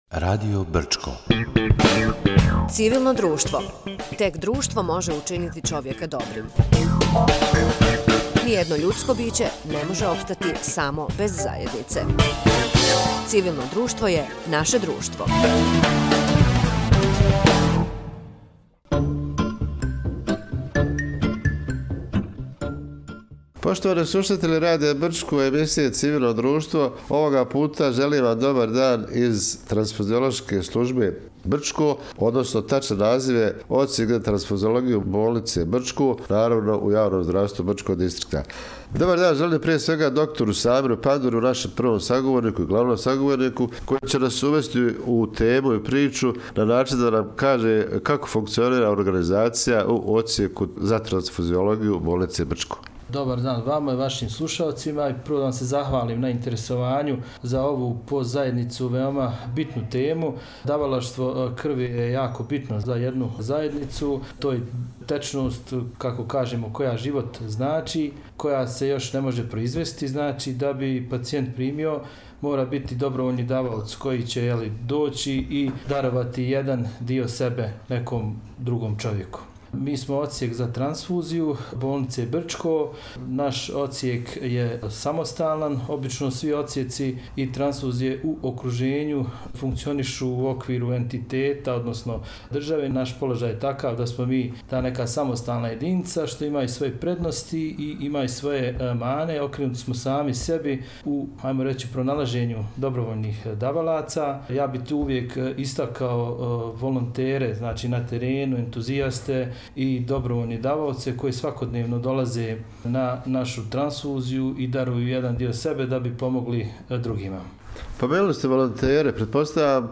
Gost emisije “Civilno društvo”